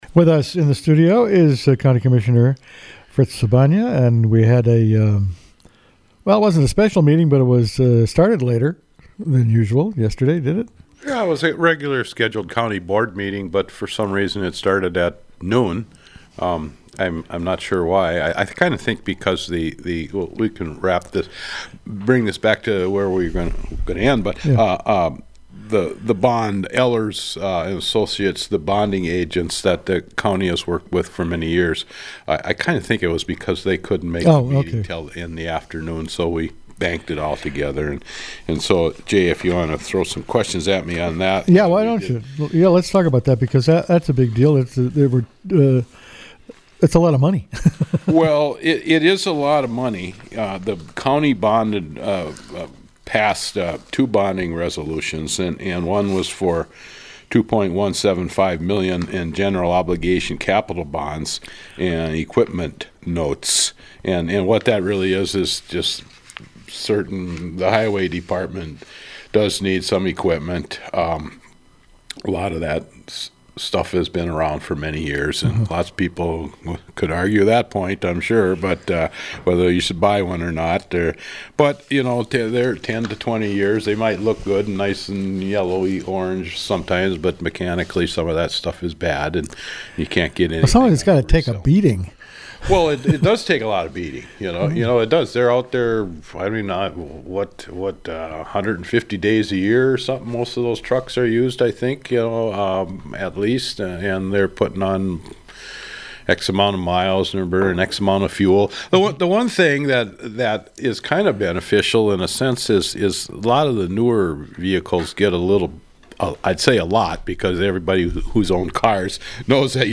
The county gave the go-ahead to two issues of general obligation bonds. Commissioner Fritz Sobanja explained how bond issuances work.